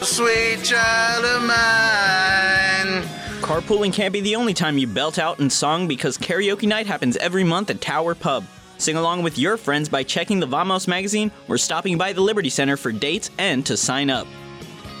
AFN Rota radio spot informing servicemembers on Naval Station Rota of MWR's new monthly karaoke night event.